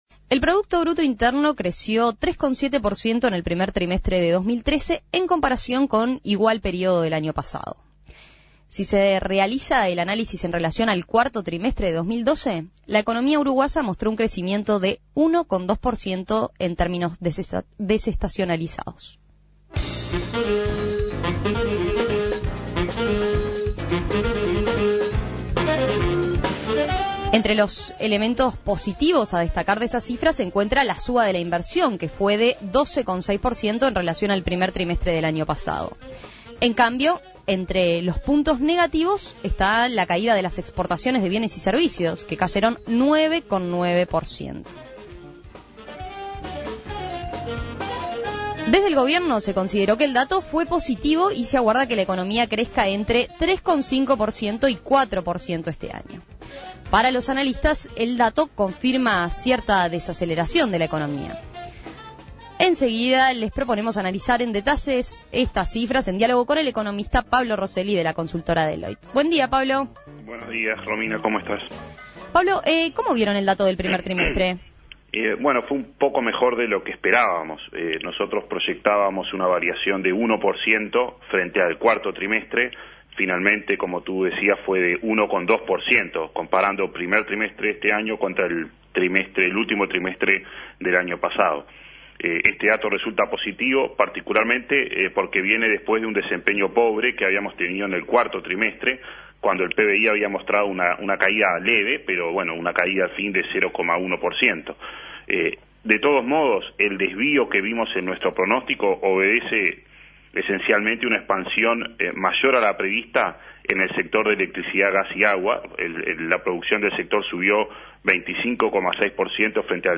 Análisis Económico El PBI creció 3,7% en el primer trimestre de 2013 en comparación con igual período del año pasado.